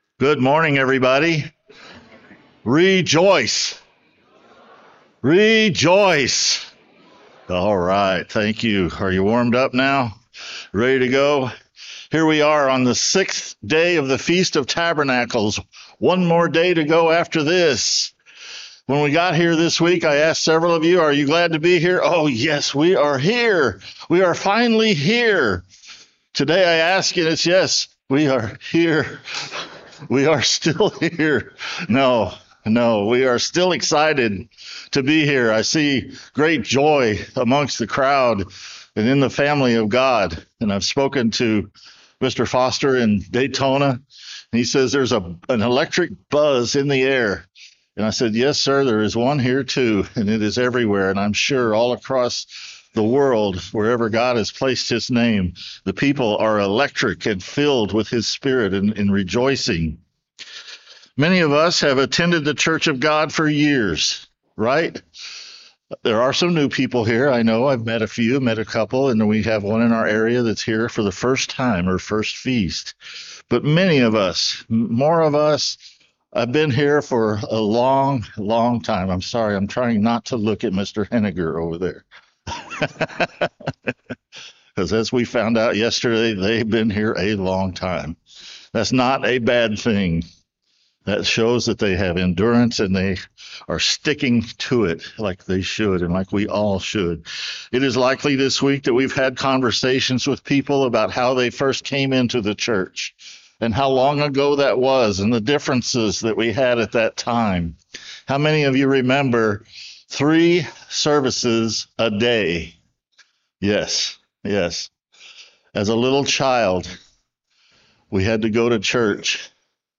We are still pressing forward, called to remain vigilant through daily study, prayer, and putting on the full armor of God. This sermon urges us to wake up, resist spiritual weariness, and remember that we are not home yet, but must endure faithfully to the end.